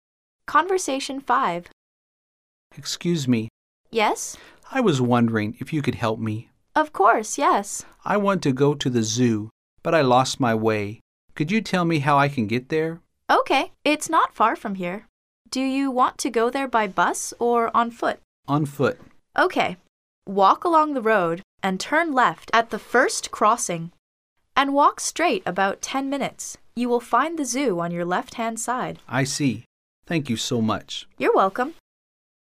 Conversation 5